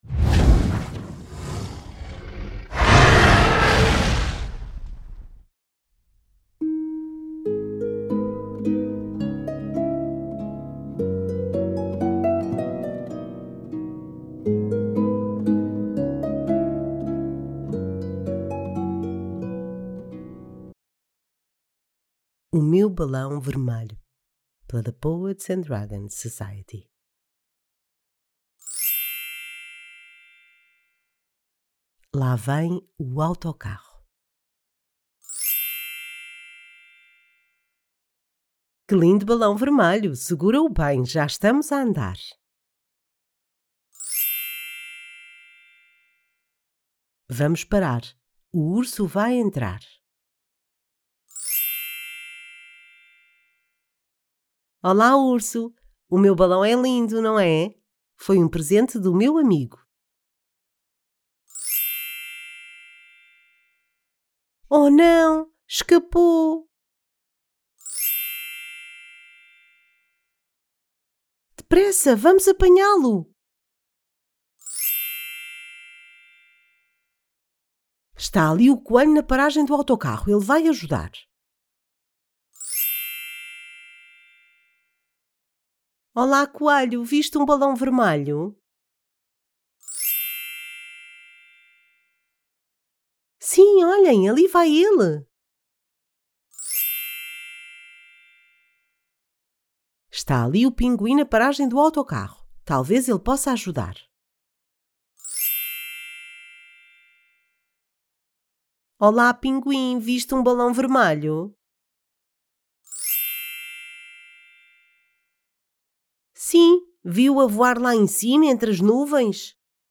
O-meu-balao-vermelho-audiobook.mp3